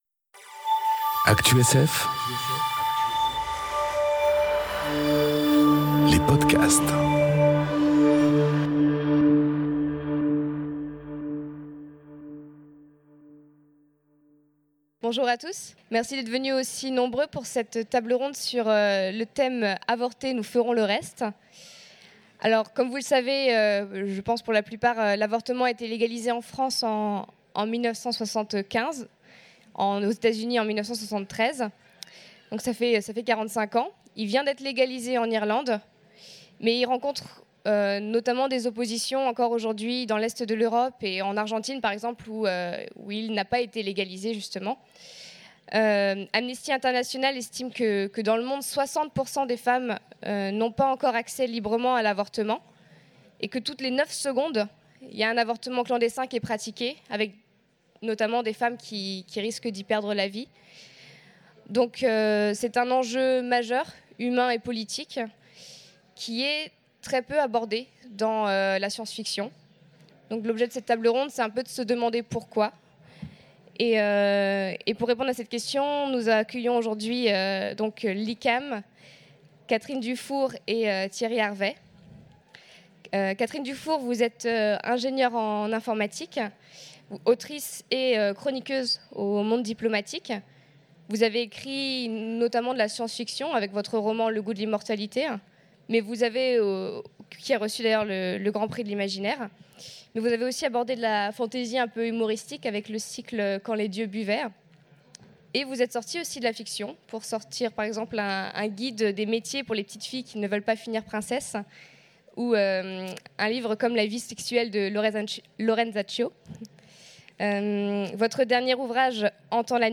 Utopiales 2018 : Conférence Avortez, nous ferons le reste